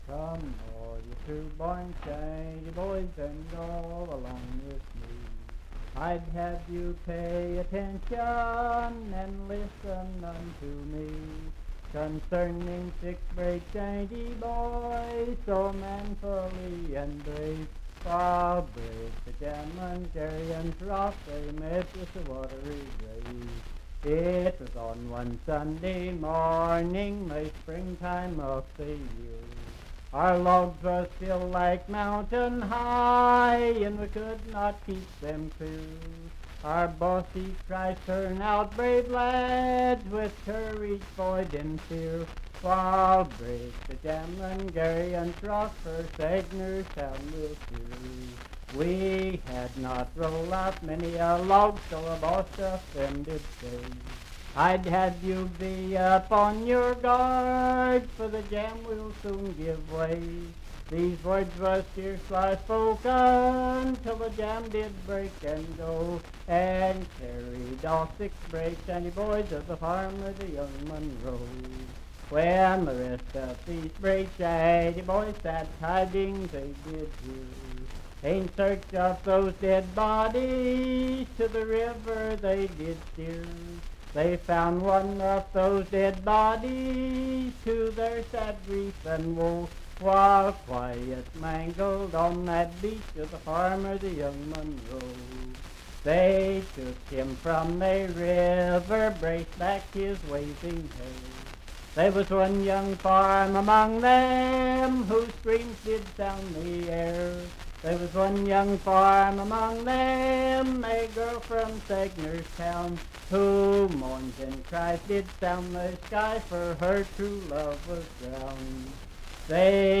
Unaccompanied vocal music
in Riverton, Pendleton County, WV
Voice (sung)